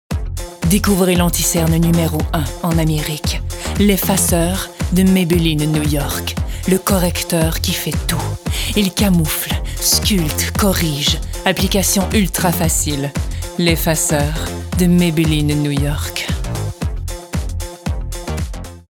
Timbre Grave - Médium
Maybelline - Charmante - Confiante - Français soutenu /
Annonceuse - Fictif 2023 0:19 762 Ko